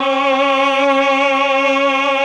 RED.CHOR1 19.wav